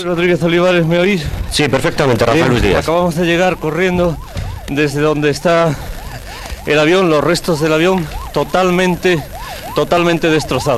Informació des de les proximitats de les restes d'un dels avions.
Informatiu